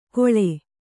♪ koḷe